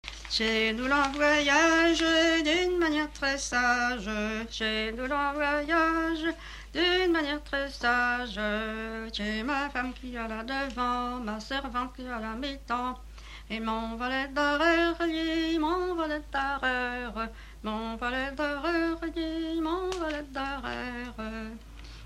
grand'danse
danse : ronde : grand'danse
Pièce musicale inédite